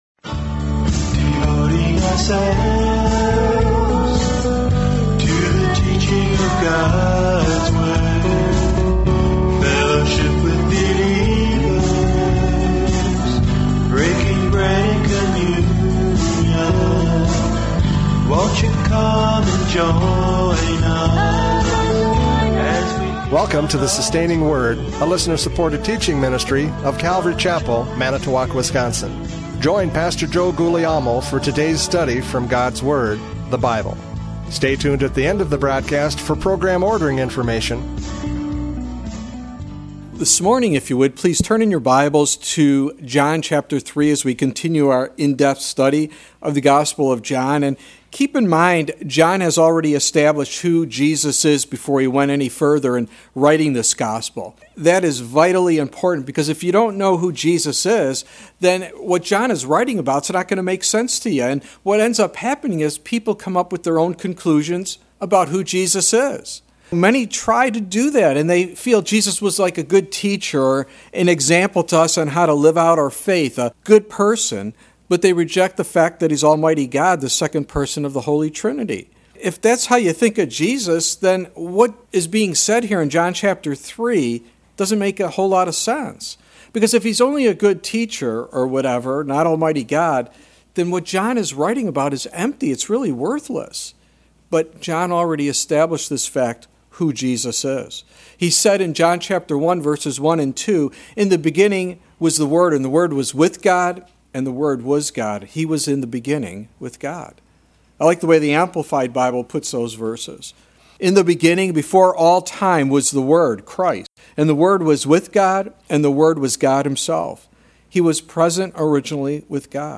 John 3:13-21 Service Type: Radio Programs « John 3:1-12 Nick at Night!